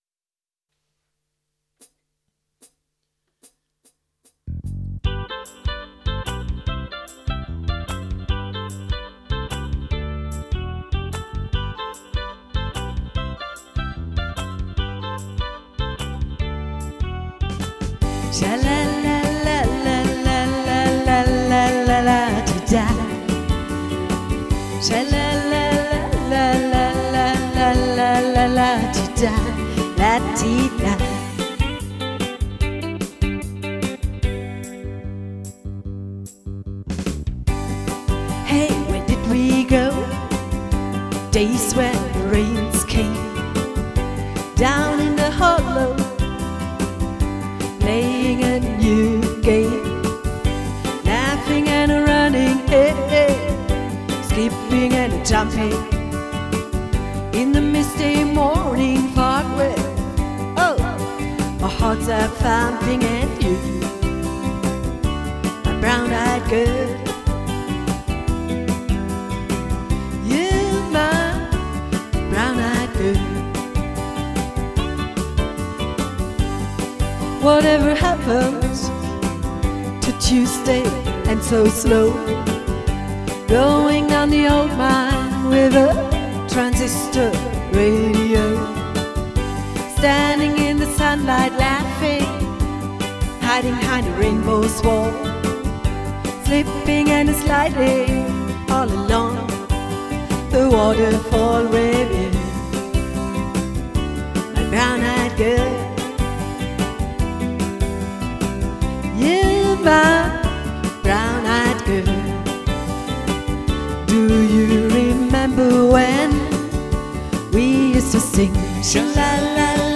Der Sänger und Gitarrist
Die Keyboarderin und Sängerin
• Duo/Trio/Quartett